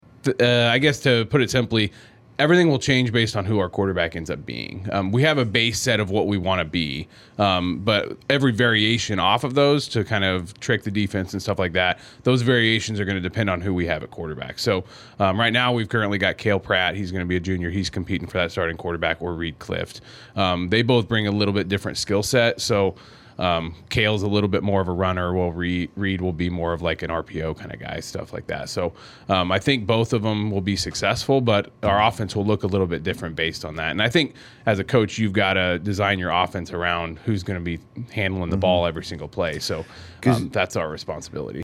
conducting the interview